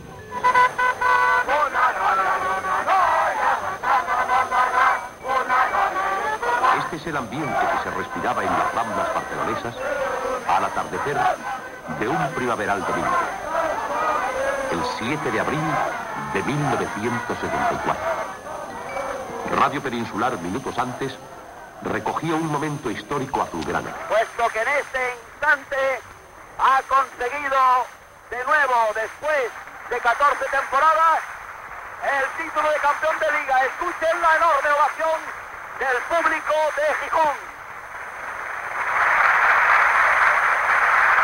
Crònica en un programa resum de la consecució del títol de Lliga masculina de futbol pel Futbol Club Barcelona, després de 14 temporades , el 7 d'abril de 1974
Esportiu